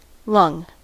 Ääntäminen
US : IPA : [lʌŋ]